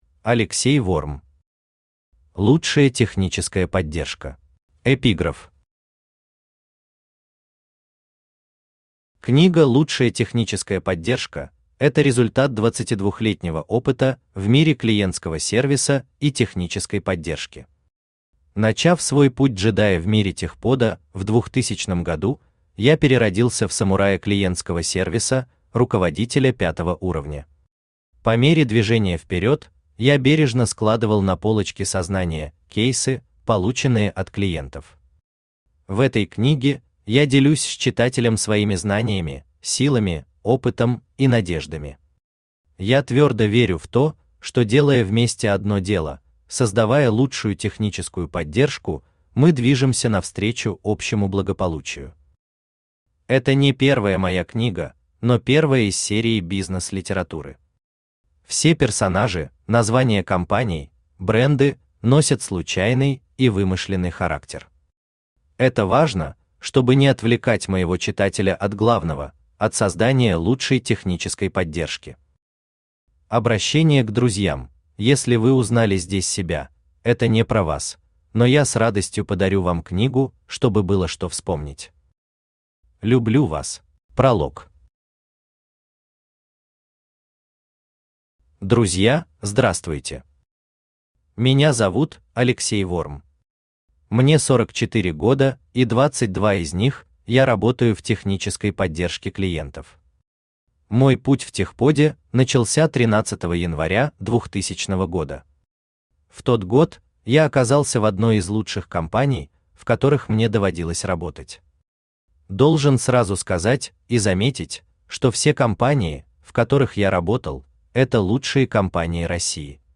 Аудиокнига Лучшая техническая поддержка | Библиотека аудиокниг
Aудиокнига Лучшая техническая поддержка Автор Алексей Ворм Читает аудиокнигу Авточтец ЛитРес.